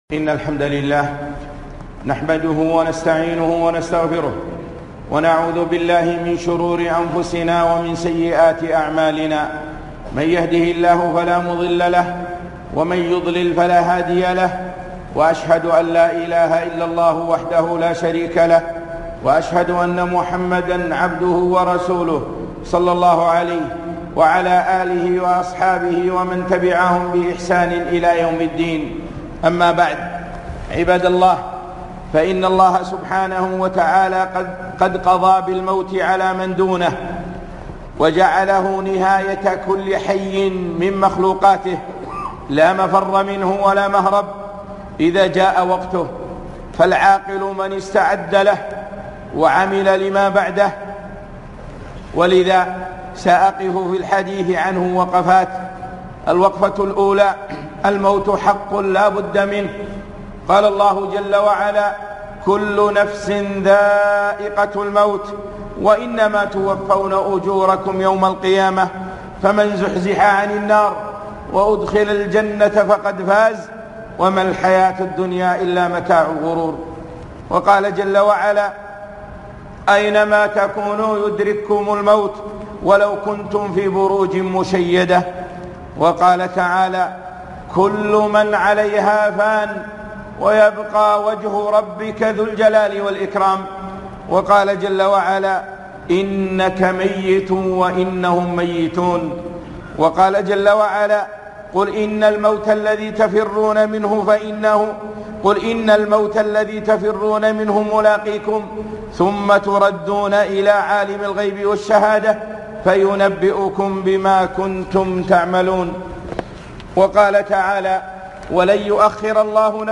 الموت - خطبة رائعة